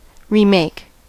Ääntäminen
France: IPA: [ʁə.pʁiz]